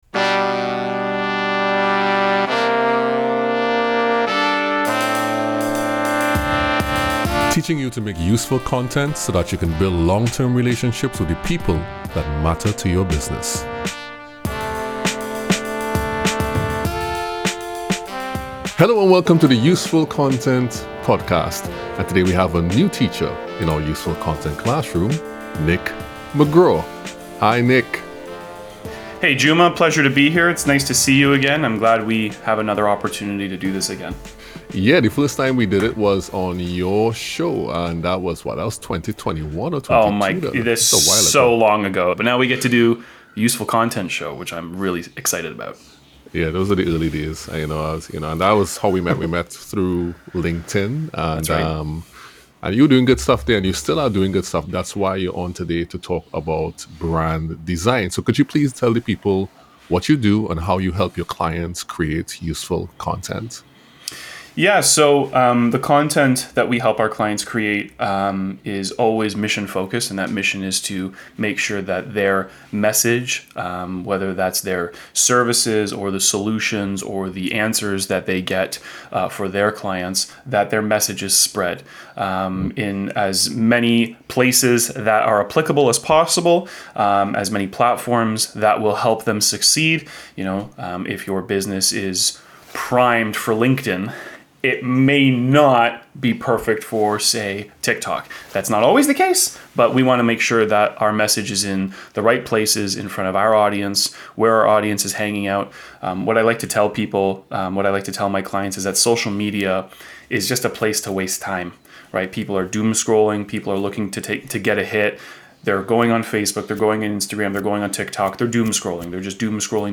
Post-Interview Reflections and Anecdotes